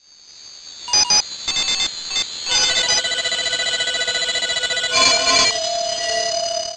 I just reversed the regular commup and slowed it down, adn this alterante one sounds hauntingly familiar.